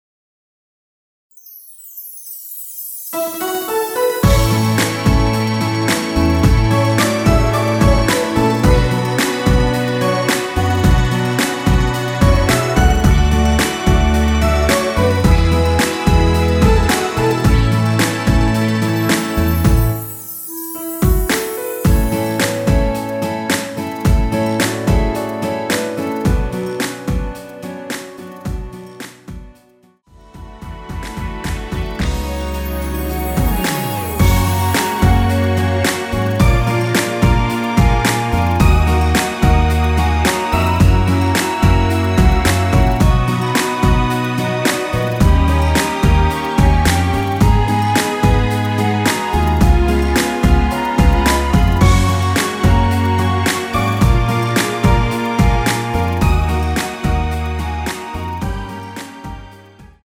원키 멜로디 포함된 MR입니다.
전주가 너무길어 시작 Solo 부분22초 정도 없이 제작 하였으며
엔딩부분이 페이드 아웃이라 엔딩을 만들어 놓았습니다.(미리듣기 참조)
앞부분30초, 뒷부분30초씩 편집해서 올려 드리고 있습니다.